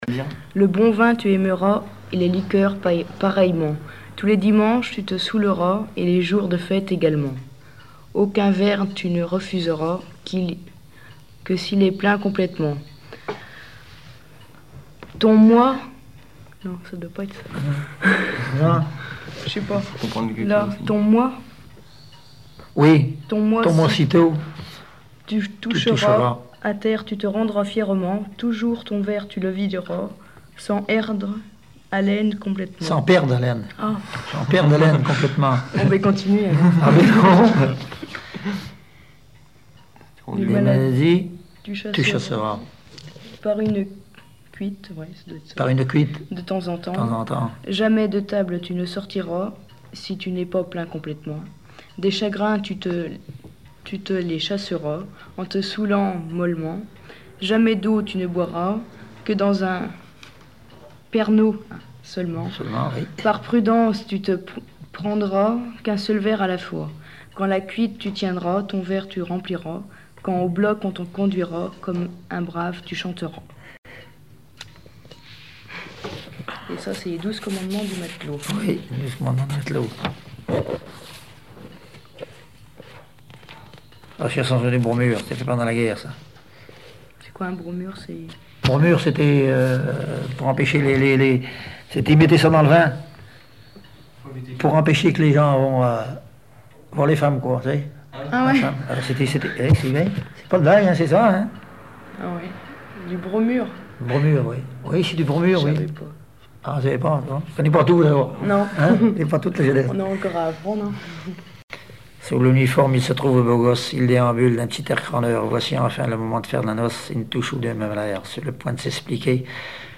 lecture du texte puis continuité en chantant
circonstance : bachique
Genre strophique
Pièce musicale inédite